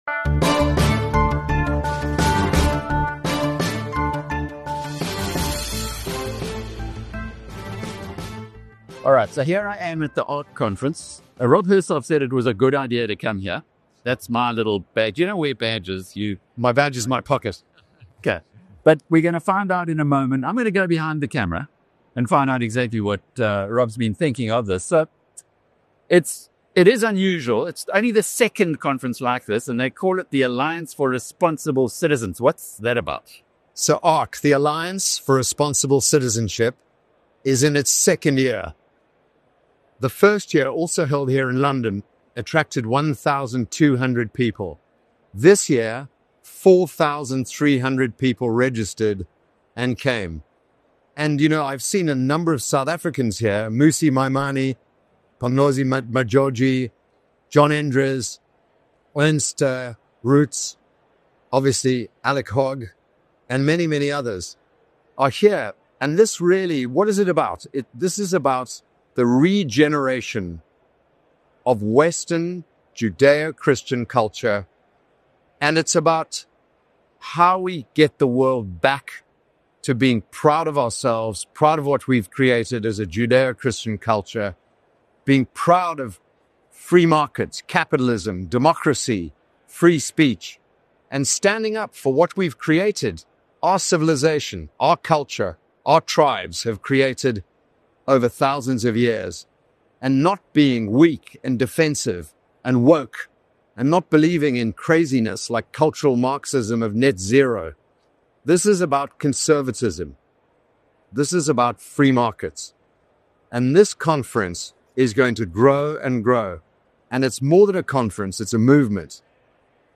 Welcome to BizNews Radio where we interview top thought leaders and business people from South Africa and across the globe.